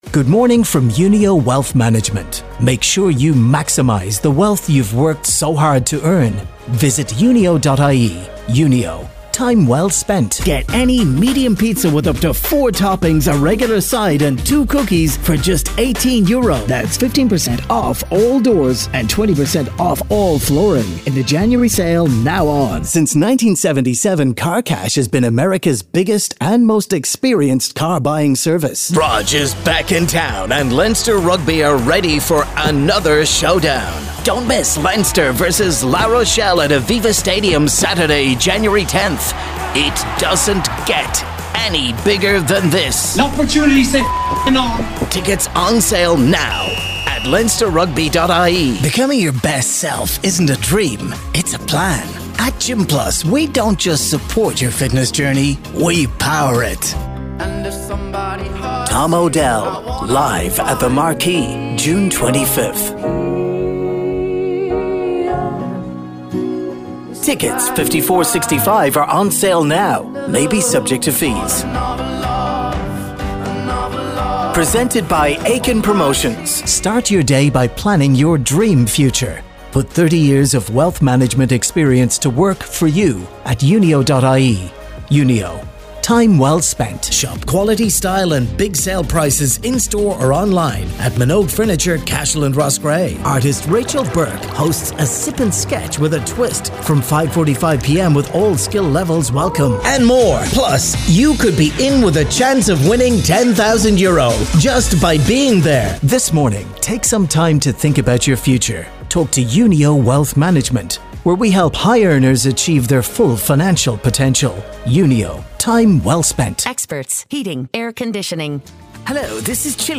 From young and upbeat to serious and professional, straight reads to characters.
Showreel
Male / 30s, 40s, 50s / English / Southern Irish Showreel http